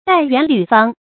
戴圓履方 注音： ㄉㄞˋ ㄧㄨㄢˊ ㄌㄩˇ ㄈㄤ 讀音讀法： 意思解釋： 履：踩著；圓、方：古人以為天圓地方。